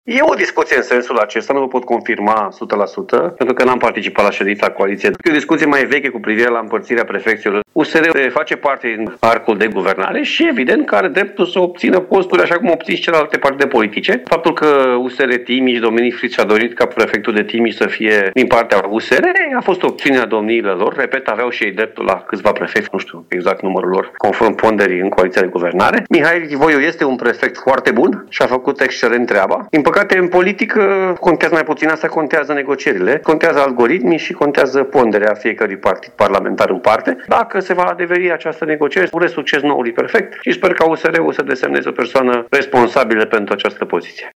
Contactat de Radio Timișoara, Simonis a spus că nu a participat la negocierile de astăzi din Coaliției, dar știe că Timișul este unul dintre județele în care Uniunea Salvați România își dorește să aibă prefect.